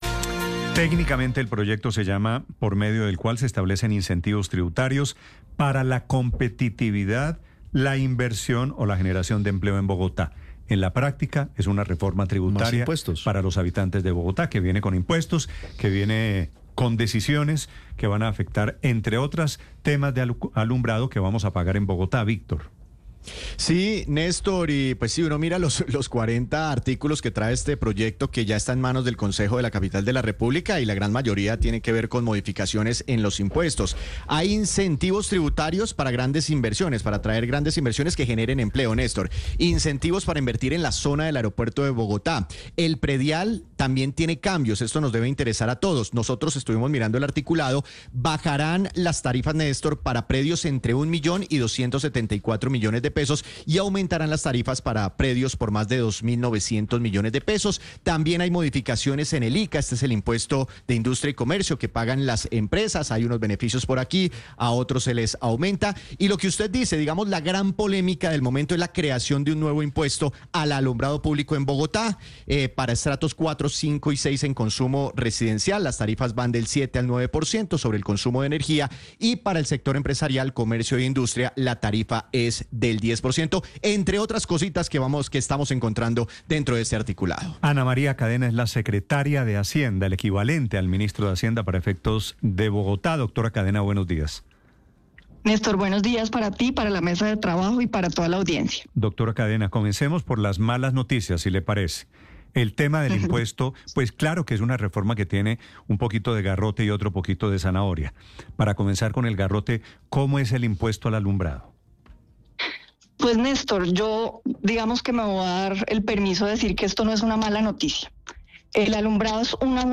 La Secretaria de Hacienda, Ana María Cadena, comenta que se está realizando cambios en la reglamentación tributaria con incentivos tributarios para la competitividad, la inversión o la generación de empleo en Bogotá, como la tributación en el alumbrado público.